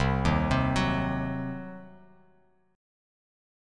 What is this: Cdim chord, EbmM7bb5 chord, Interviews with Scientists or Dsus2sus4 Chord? Cdim chord